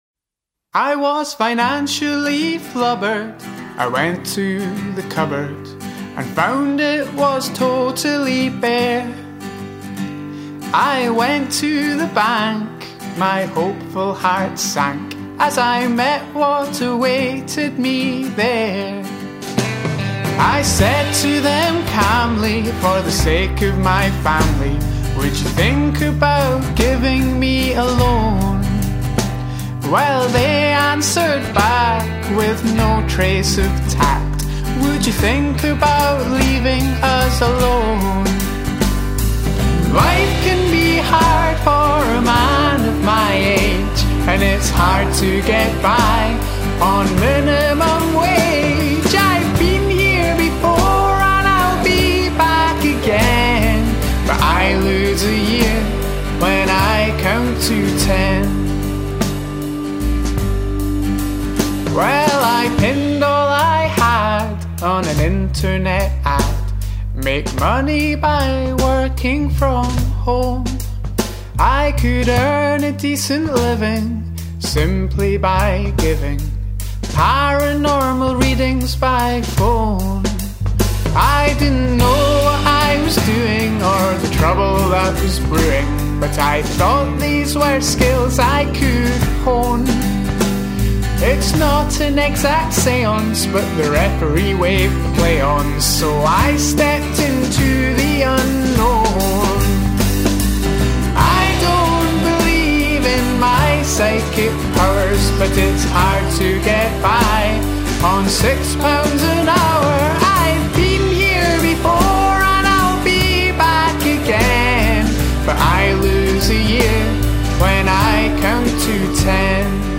an 8-piece Glasgow-based folk-pop band.